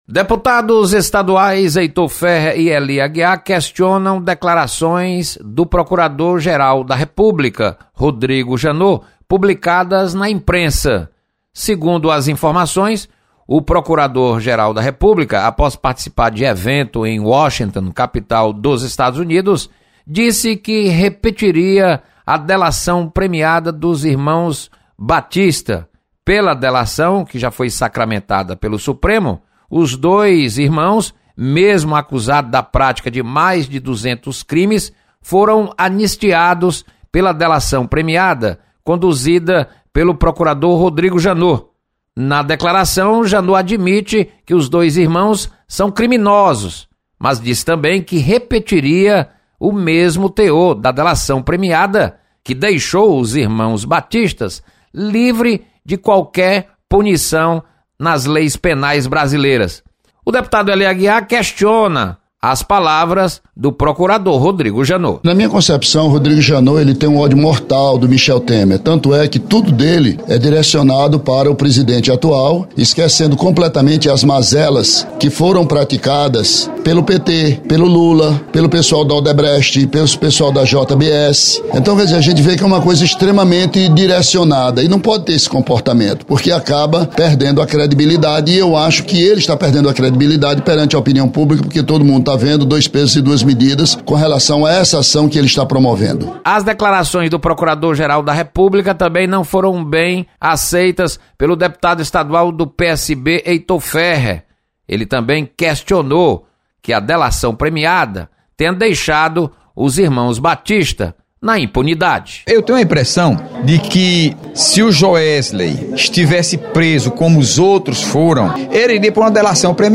Deputados criticam postura do procurador-geral da República, Rodrigo Janot. Repórter